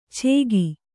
♪ chēgi